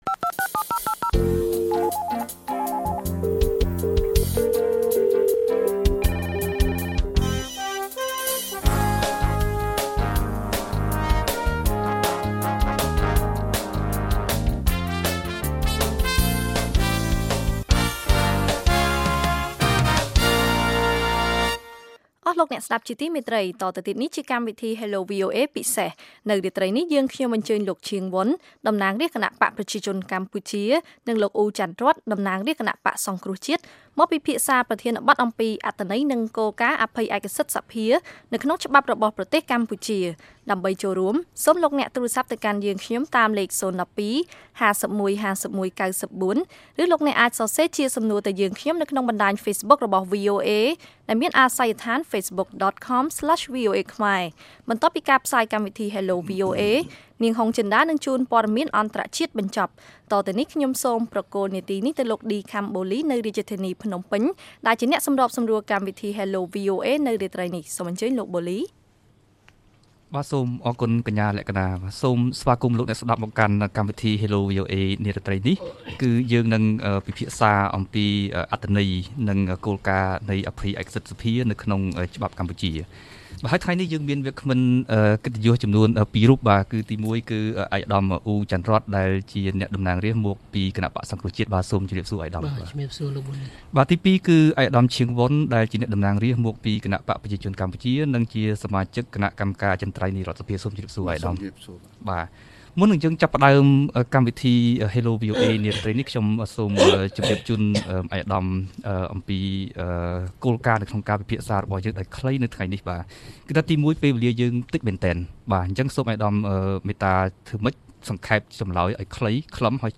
លោក អ៊ូ ច័ន្ទរ័ត្ន តំណាងរាស្ត្រមកពីគណបក្សសង្គ្រោះជាតិ និងលោក ឈាង វុន តំណាងរាស្ត្រមកពីគណបក្សប្រជាជនកម្ពុជា បានពិភាក្សាអំពីអត្ថន័យ និងគោលការណ៍នៃអភ័យឯកសិទ្ធិសភានេះ នៅក្នុងកម្មវិធី Hello VOA ពិសេស កាលពីថ្ងៃអង្គារ ទី៣១ ខែឧសភា ឆ្នាំ២០១៦។